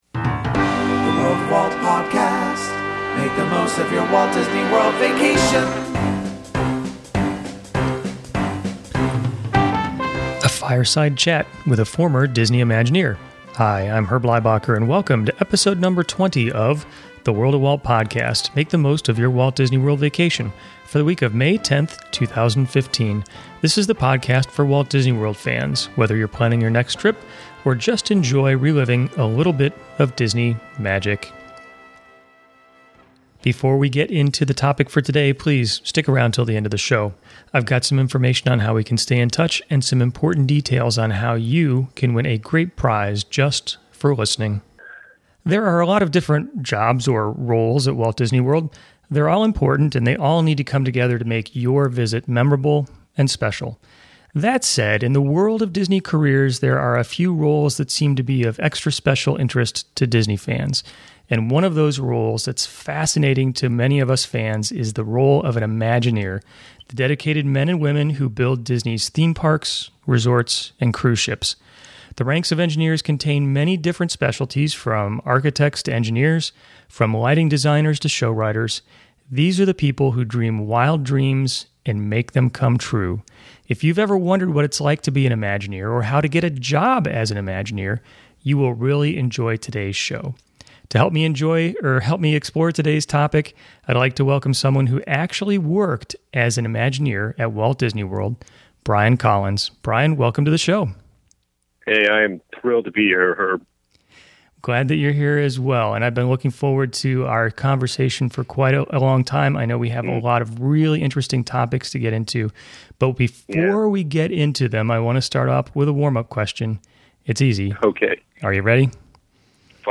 A Fireside Chat with a Former Disney Imagineer